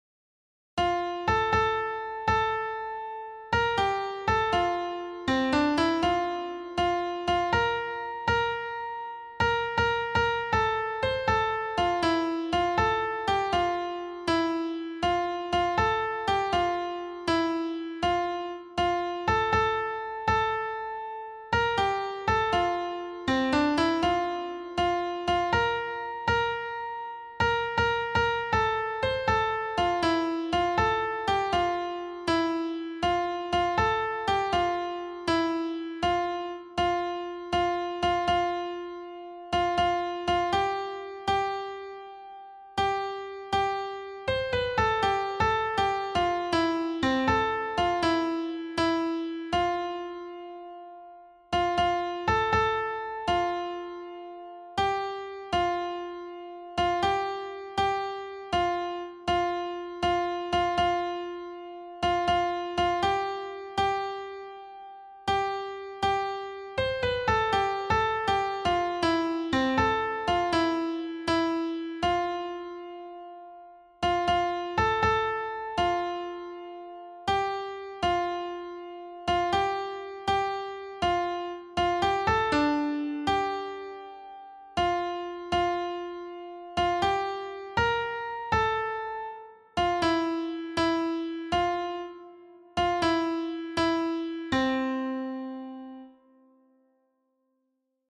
alti-mp3
boga-alt.mp3